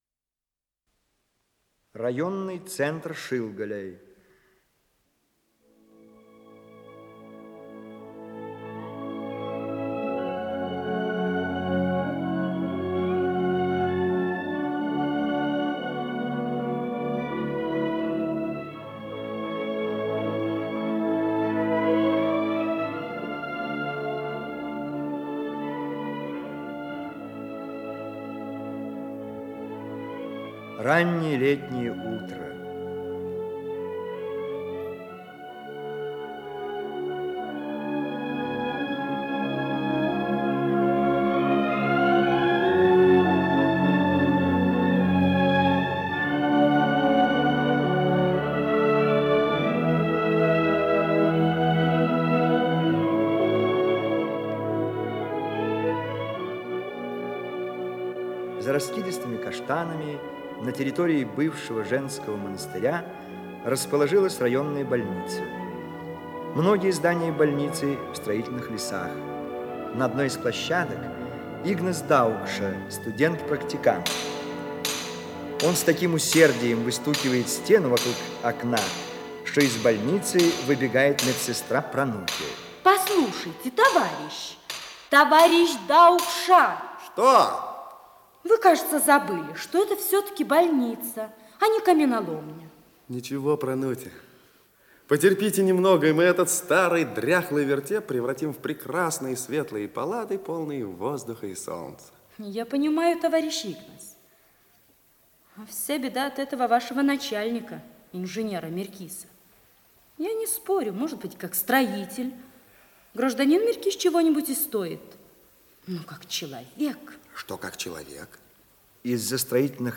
Исполнитель: Артисты Вильнюсского Государственного русского драматического театра
Радиокомпозиция спектакля